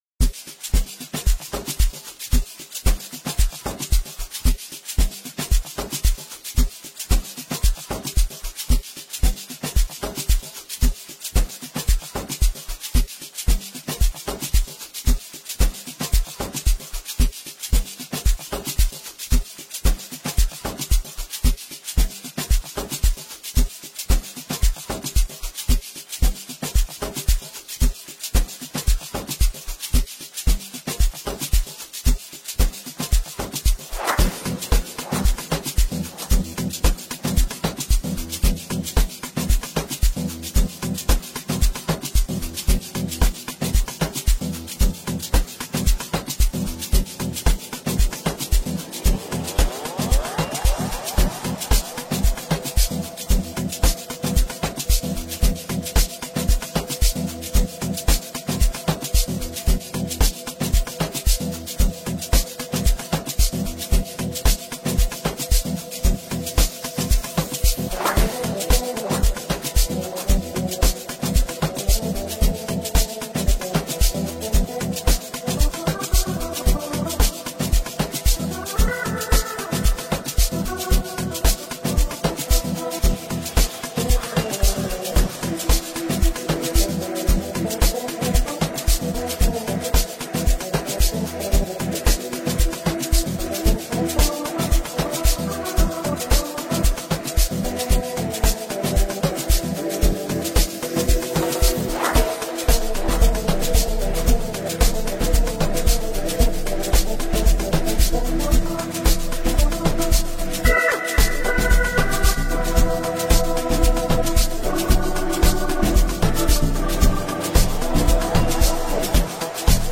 banging Amapiano instrumental